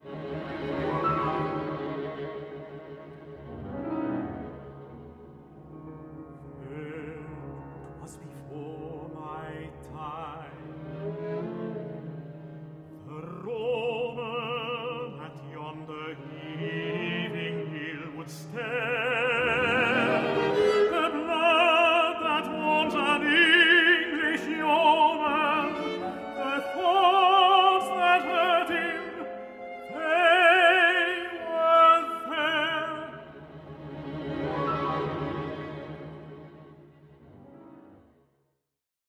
for tenor, piano and string quartet
Orchestral